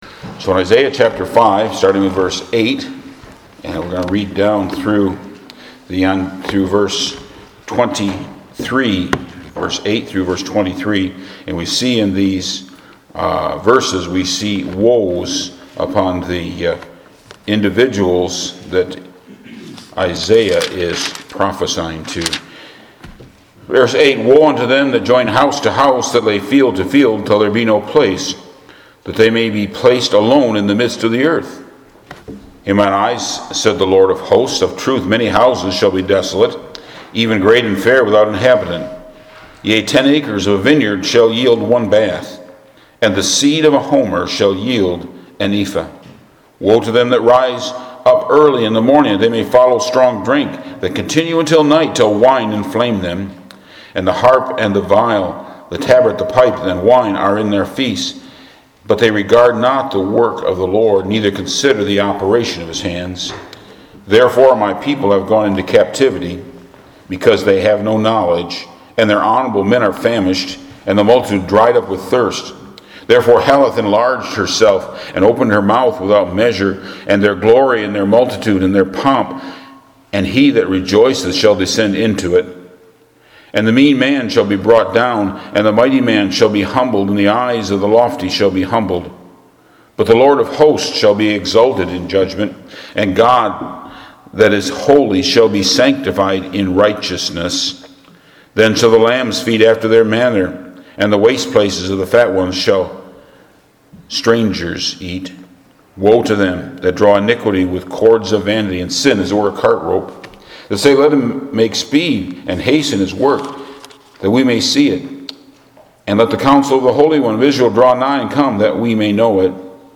Sermon MP3